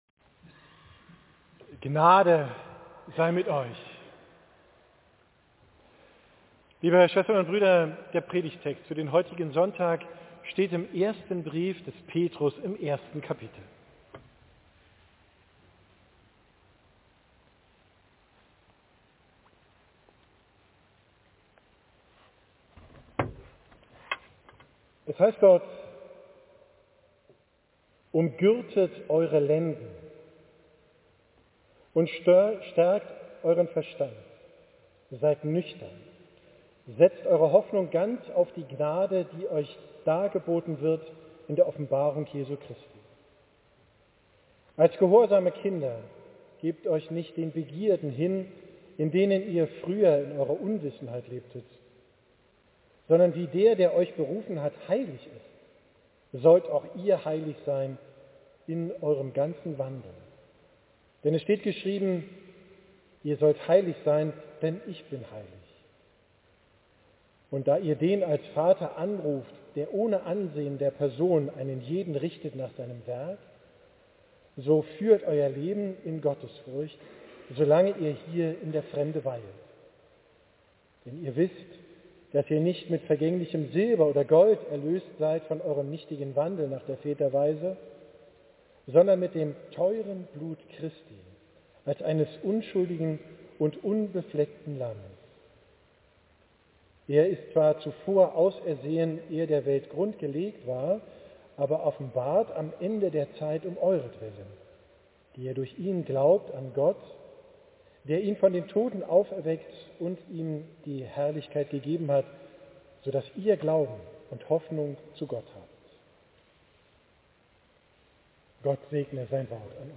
Predigt vom Sonntag Okuli, 3.